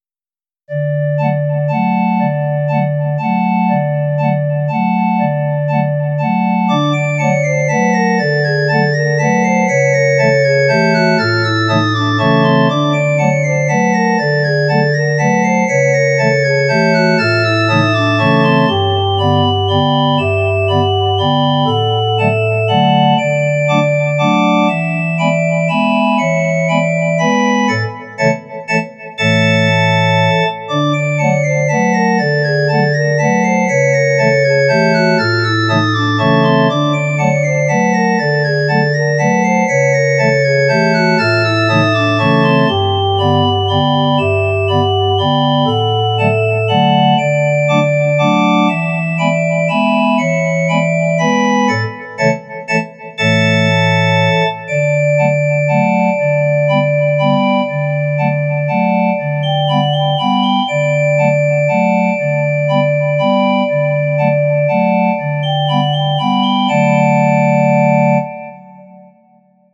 良い雰囲気になってきたのだ！
witchcircus_happy.mp3